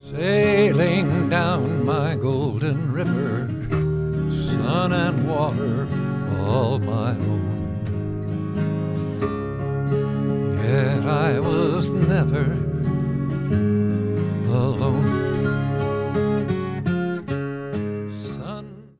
voice, 12-string guitar